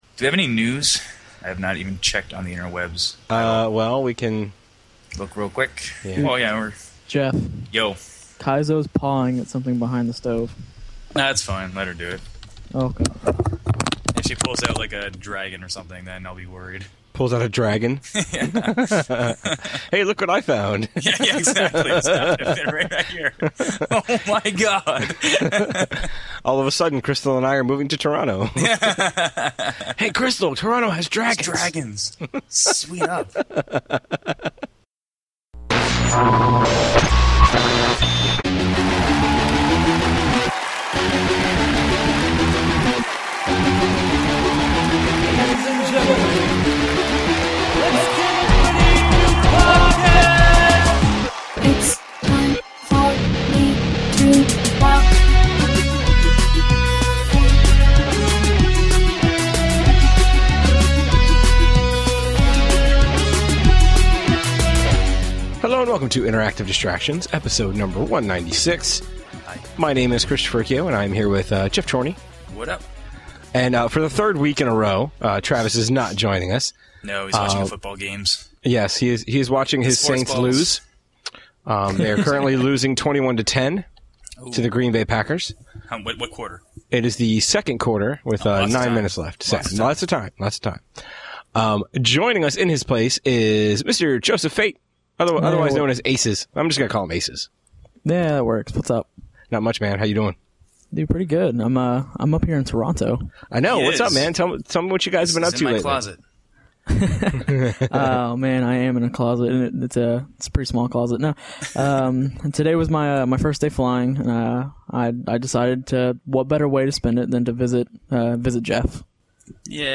After the 8-bit break, we talk about some games that may show up on GotY lists that you may not be expecting. Finish off with a lengthy release list, a couple of voice mails and a new question of the week and we are less than a month from InDisFest!